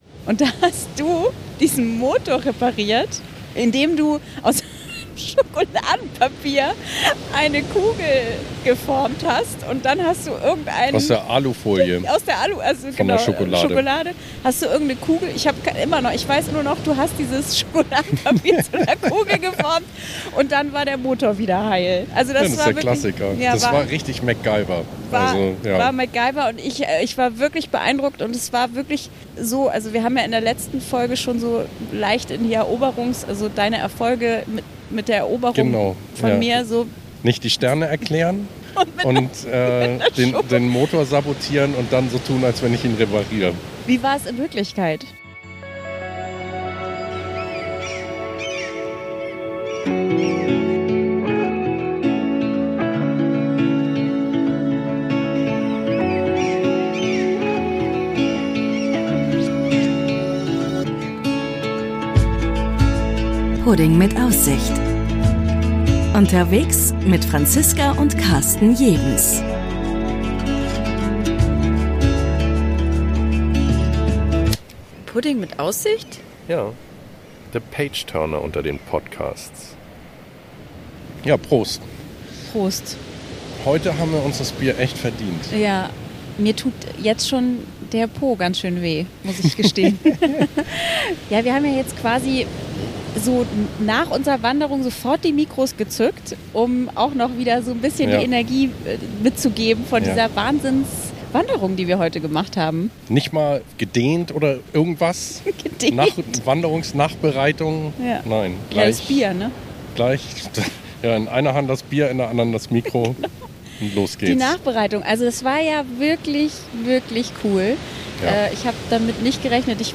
Gesund und munter zurück am Landy haben wir natürlich gleich die Mikros gezückt, um unsere Begeisterung mit Euch zu teilen. In dieser Folge wird sich ein bisschen fremdgeschämt, wir gestehen uns ein, wie dumm wir einst waren, drehen noch eine kleine Runde durch unsere nautische und punkige Vergangenheit und trennen uns von alten Träumen.